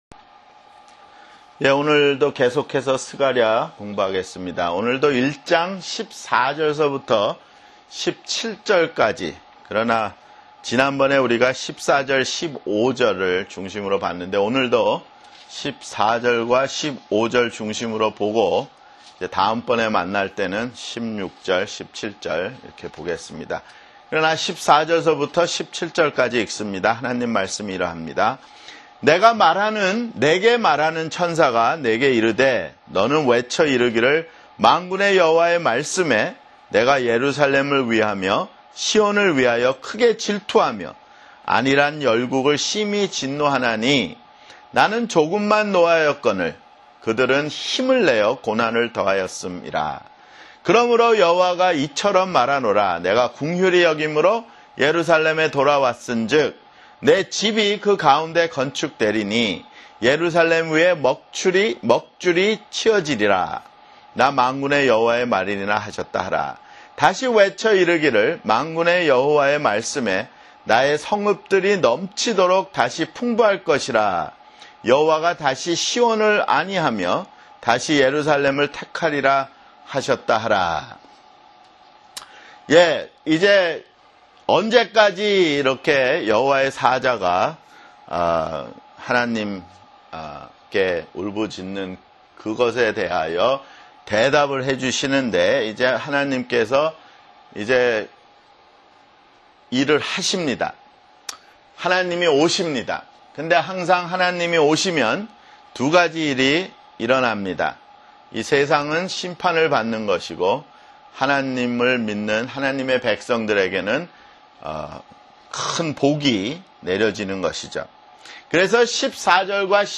[성경공부] 스가랴 (10)